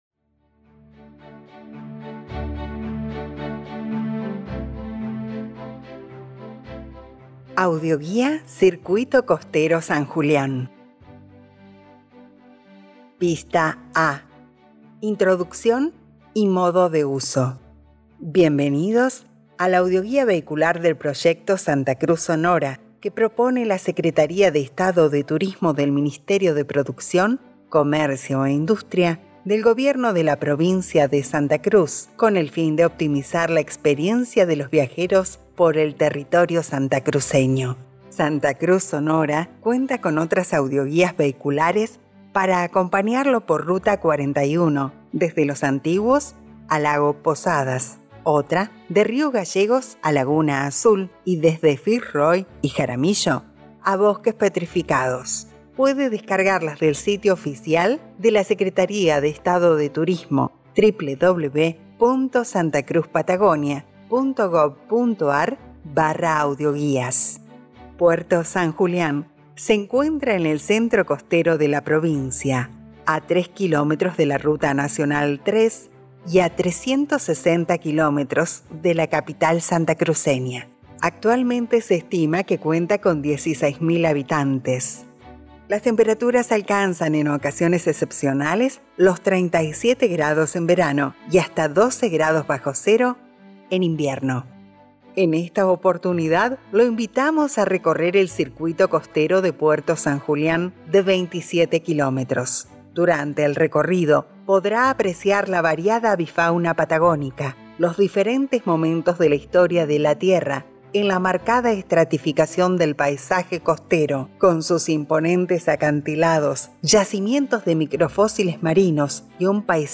Además, y para que esta propuesta sea más amena, incluimos también producciones musicales de nuestros artistas, que ilustran el paisaje y dan color al viaje.
Audioguía Vehicular Huelgas Patagónicas